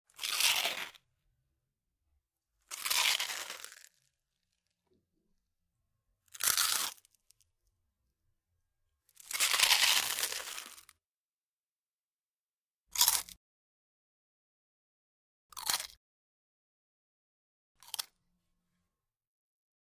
Звуки хруста чипсов
Все звуки записаны в высоком качестве и доступны бесплатно.
8. Хрум-хрум